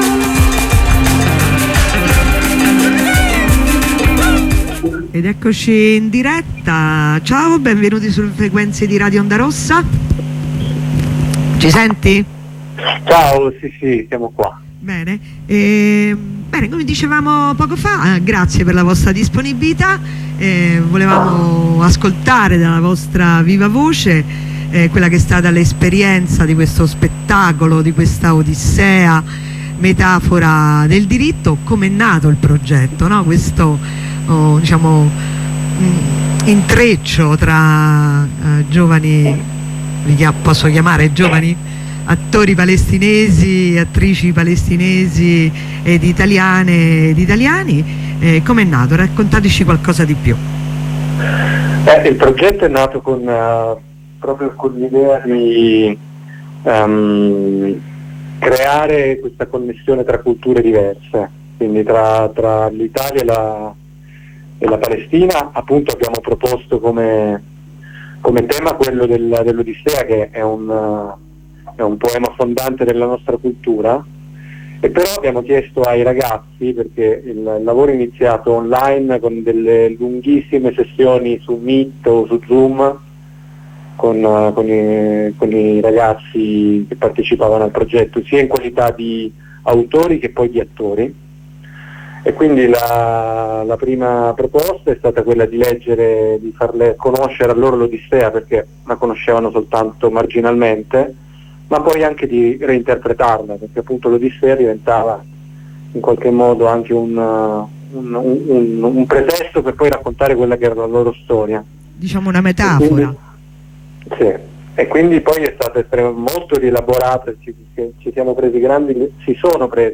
Telefonata con studentessa da Milano